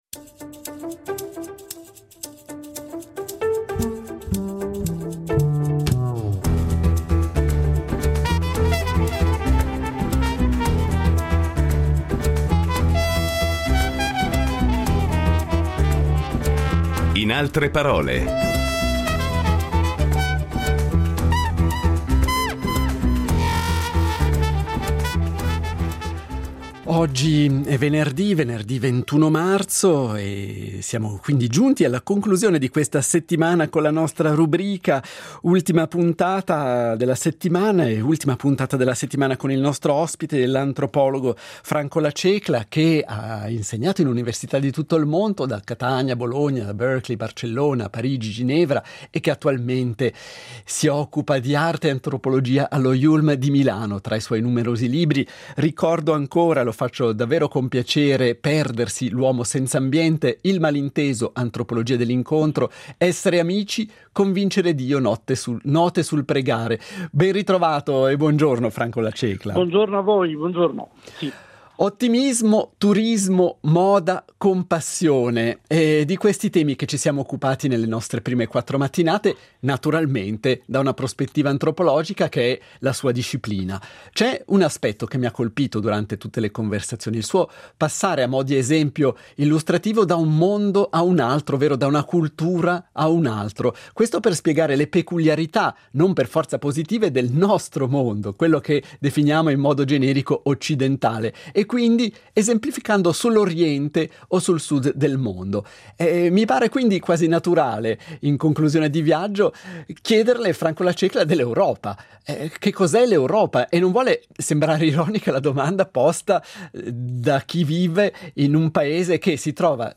Incontro con Franco La Cecla, antropologo culturale e scrittore